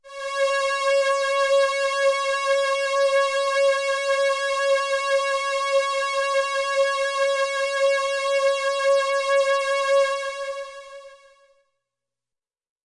标签： MIDI-速度-96 CSharp6 MIDI音符-85 罗兰-JX- 3P 合成器 单票据 多重采样
声道立体声